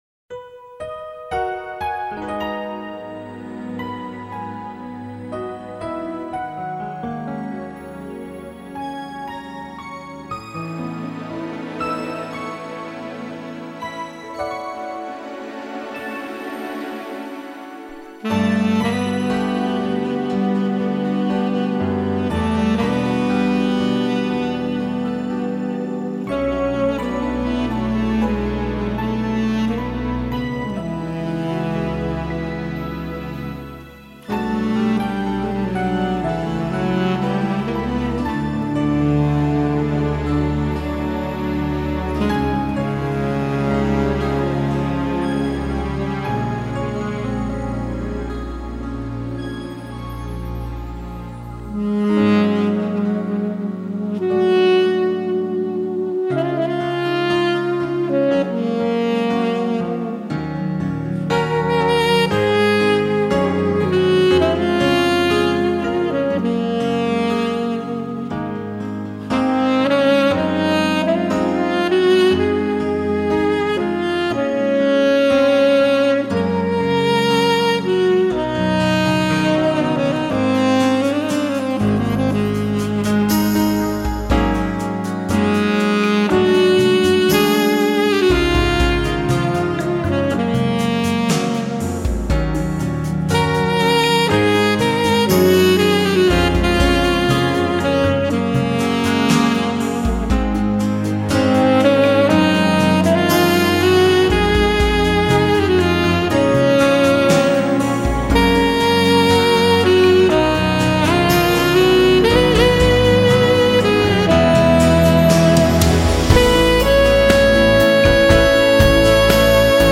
柔情薩克斯風
他..給我們貼心的撫慰 他..給我們萬般的沉醉 他..讓薩克斯風音化為一種酣甜的幸福氣味
薩克斯風更增添其催淚指數